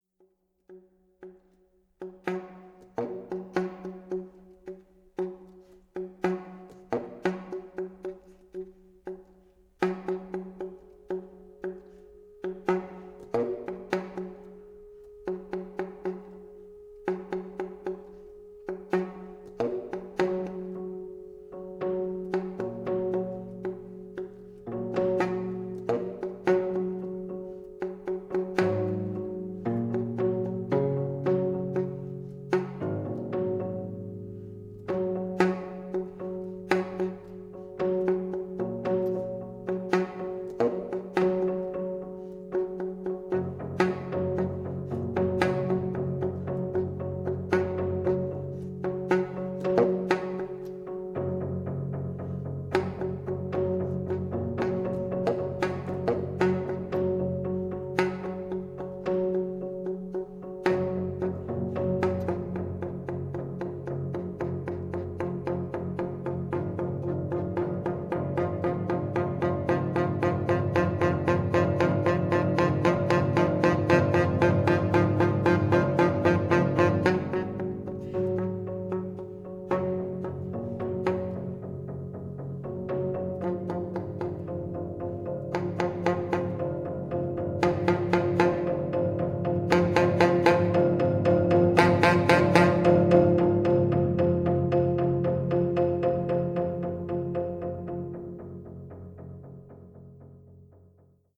soprano and tenor saxophone
piano and voice